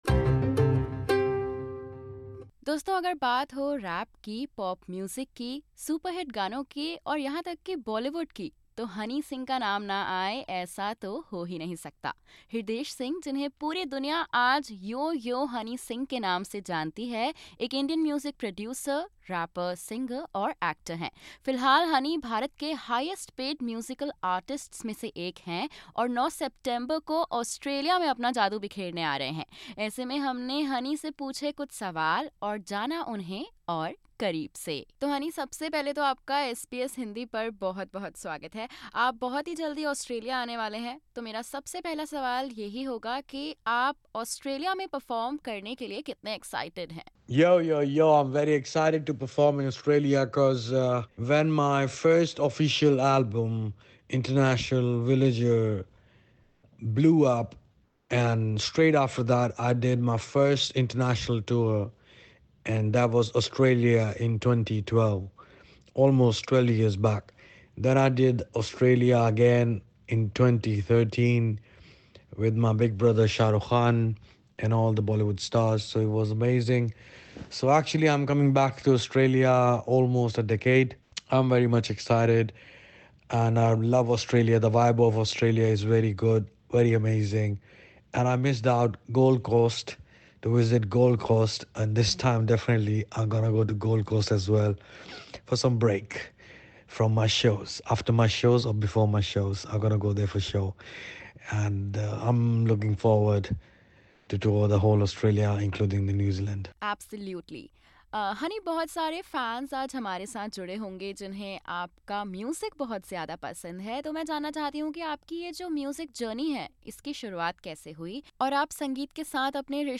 In this candid chat, he reveals his Australia plans, talks about his album trending worldwide, overcoming the dark phase of his life and also shares some honest advice for upcoming rappers.